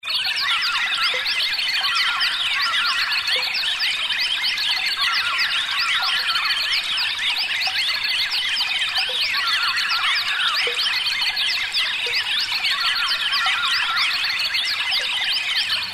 溪流上的鸟儿
Tag: 120 bpm Ambient Loops Fx Loops 2.69 MB wav Key : Unknown